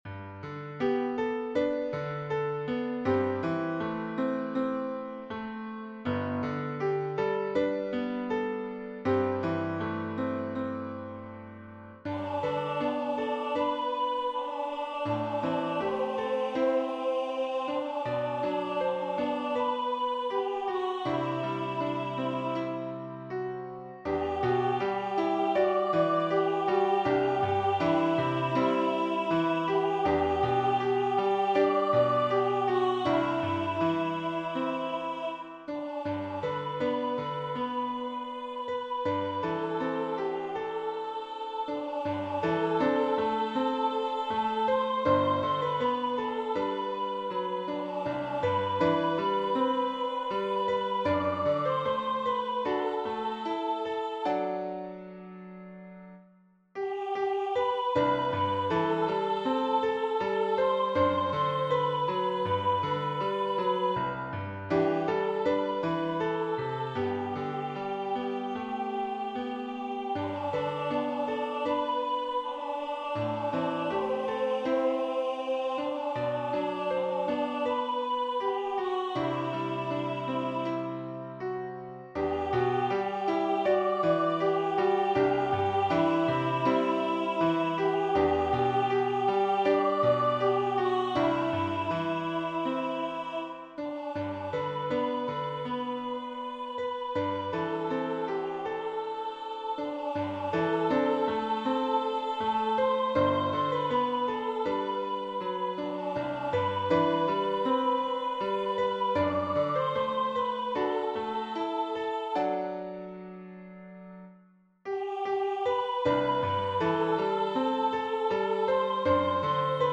High Voice/Soprano